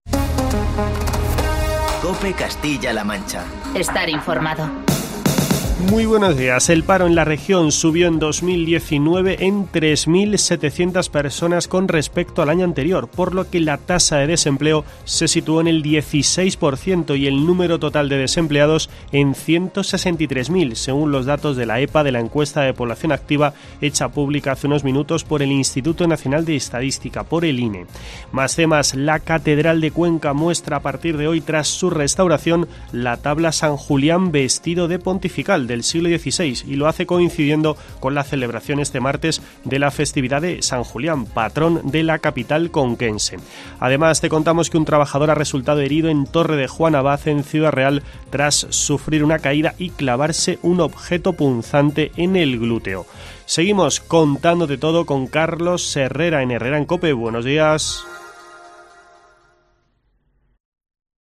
boletín informativo